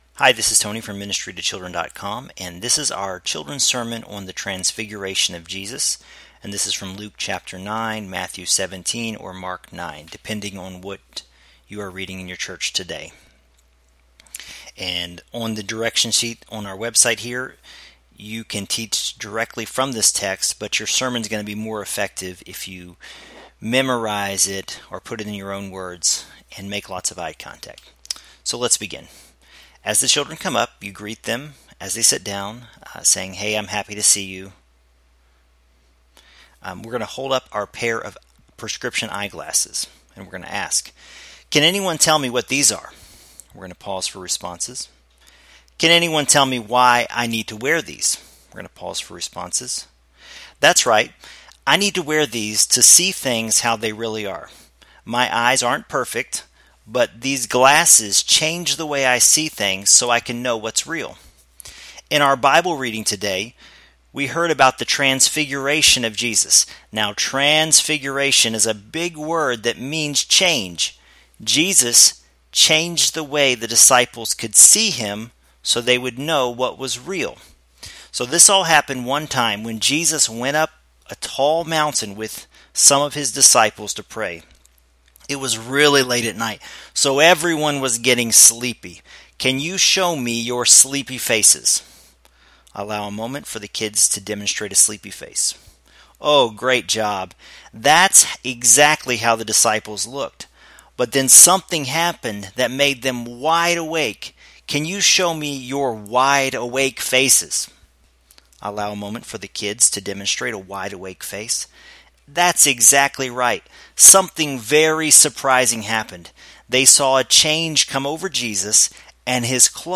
Audio example of our Transfiguration of Jesus children’s sermon
Children’s-Sermon-on-the-Transfiguration-of-Jesus.mp3